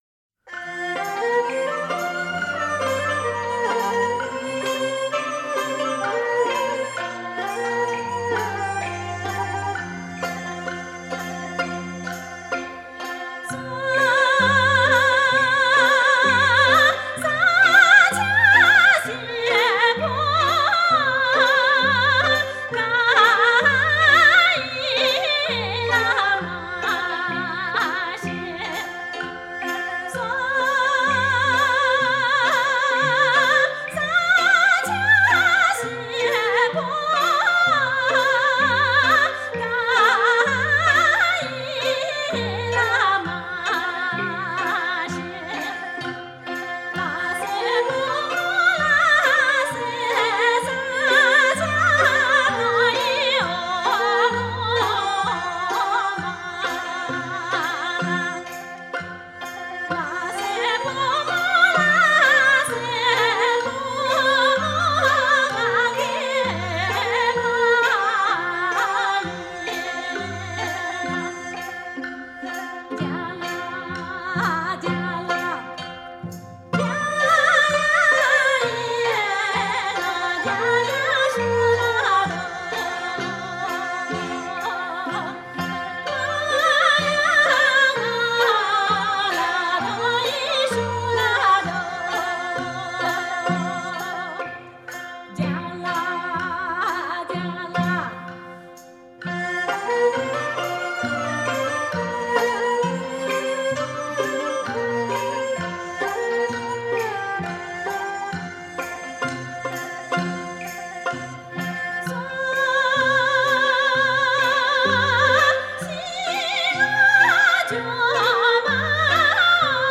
弦子曲    3:25